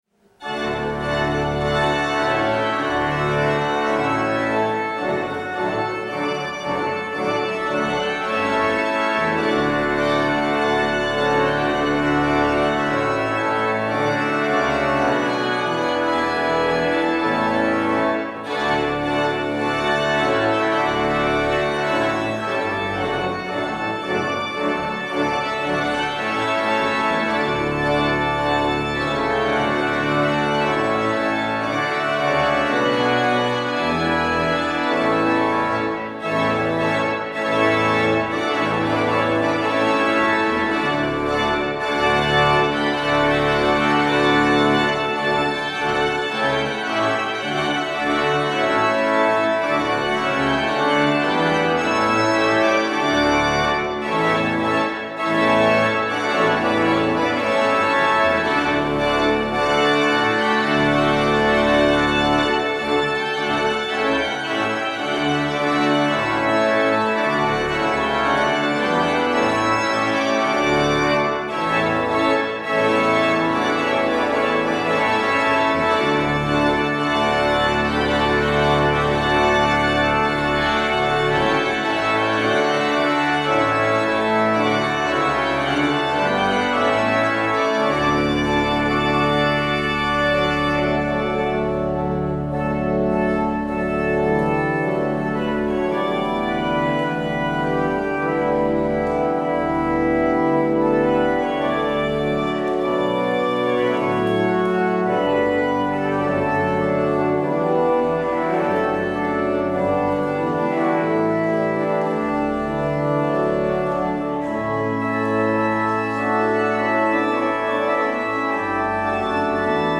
 Luister deze kerkdienst terug
Daar de Alle-Dag-Kerk in Amsterdam door de lock down tijdelijk gesloten is, zenden wij een herhaling uit van de dienst gehouden op 3 januari 2018.